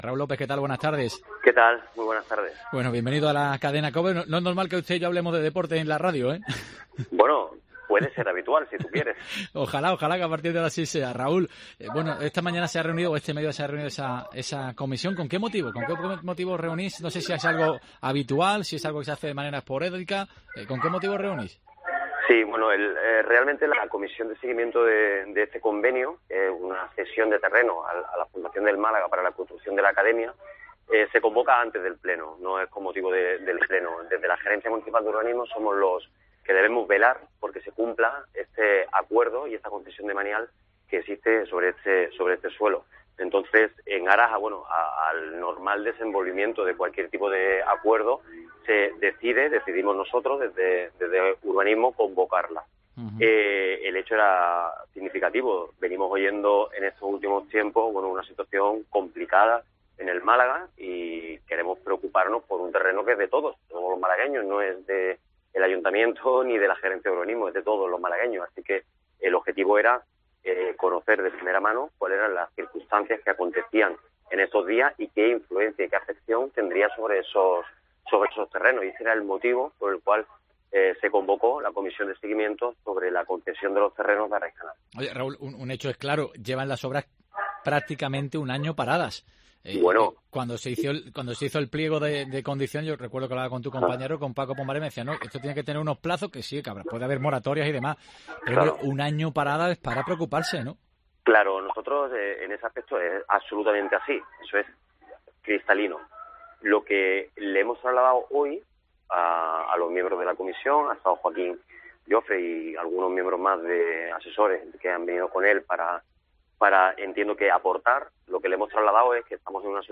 Escucha aquí la entrevista con el concejal de Urbanismo, Raúl López